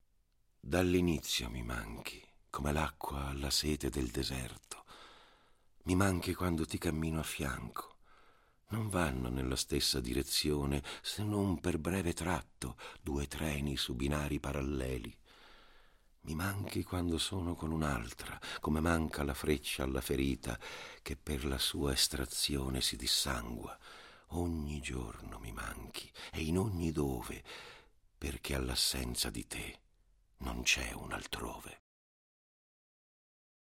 Spettacolo teatrale di poesia e musica "Una vita per il suo verso" di Corado Calabrò.